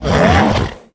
minotaur_attack.wav